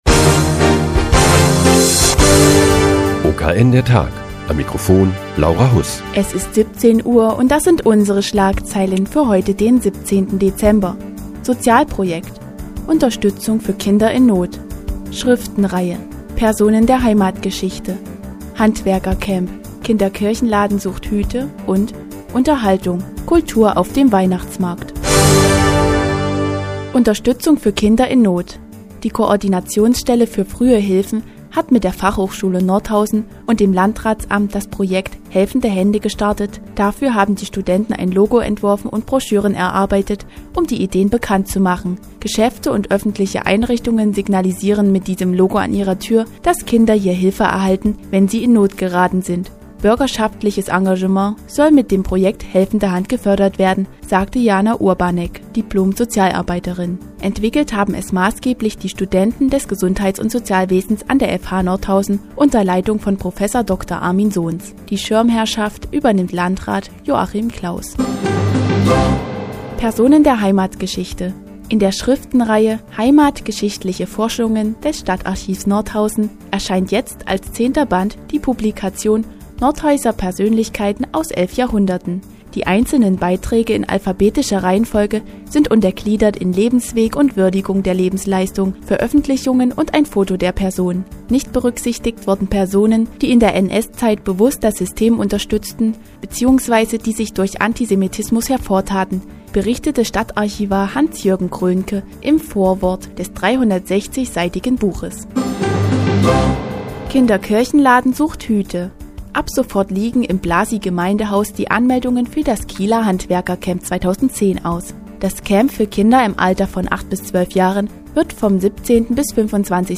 Die tägliche Nachrichtensendung des OKN ist nun auch in der nnz zu hören. Heute geht es um das Projekt "Helfende Hand" und den Sondershäuser Weihnachtsmarkt.